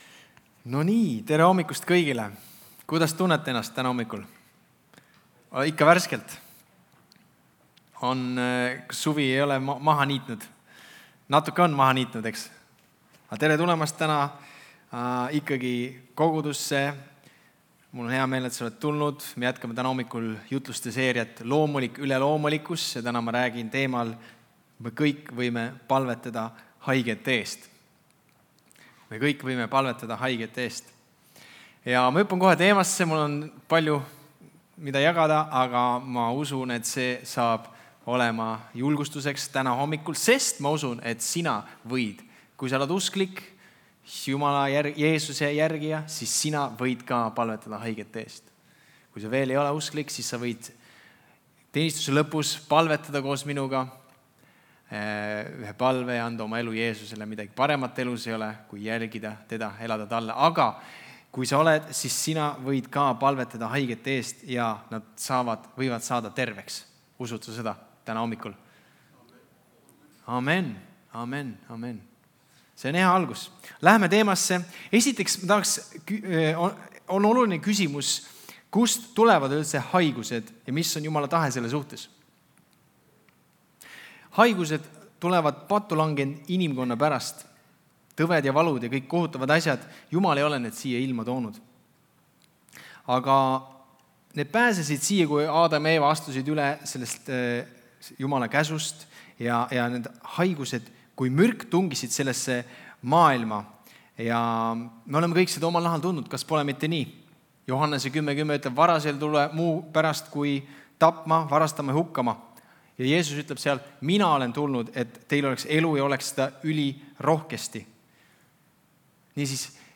Jutlused - EKNK Toompea kogudus
Kristlik ja kaasaegne kogudus Tallinna kesklinnas.